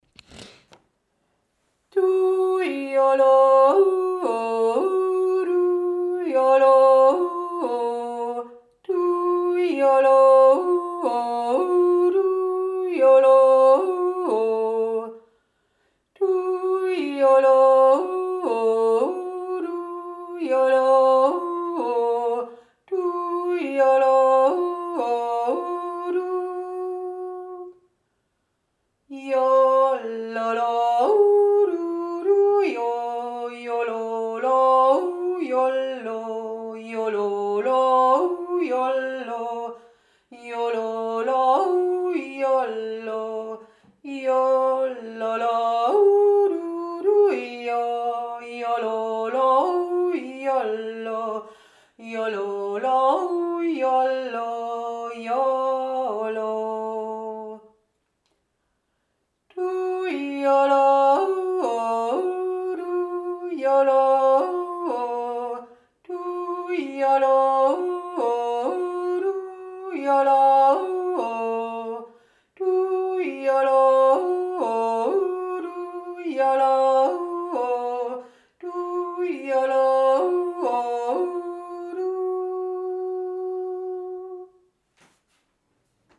JA hier findet ihr die Aufnahmen unserer Jodler , von mir eingesungen.
Der Allgäuer Doppeljodler